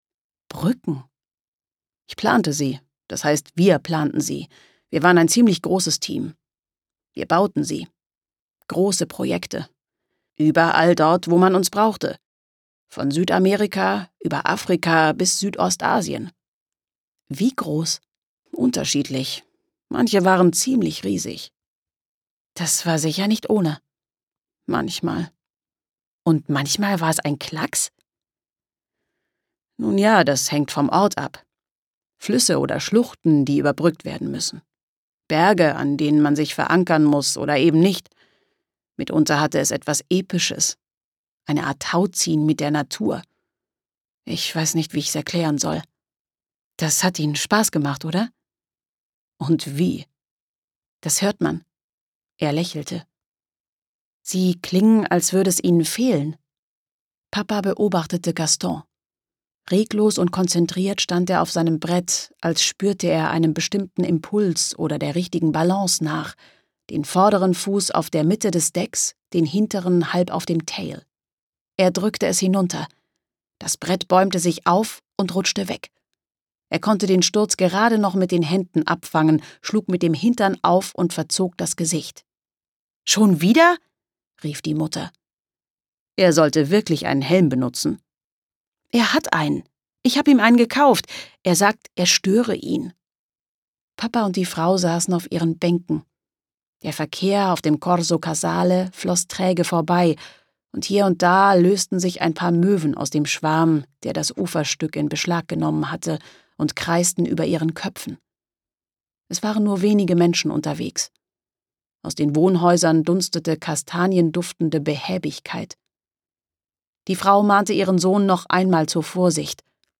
2020 | Ungekürzte Lesung